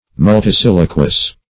Search Result for " multisiliquous" : The Collaborative International Dictionary of English v.0.48: Multisiliquous \Mul`ti*sil"i*quous\, a. [Multi- + siliquious.]
multisiliquous.mp3